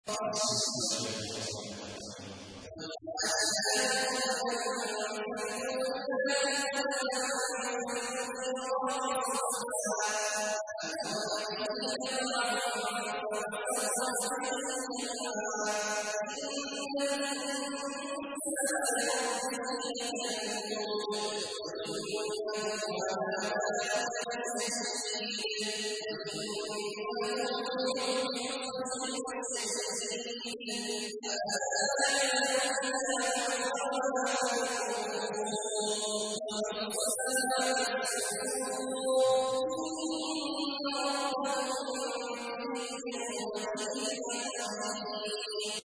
تحميل : 100. سورة العاديات / القارئ عبد الله عواد الجهني / القرآن الكريم / موقع يا حسين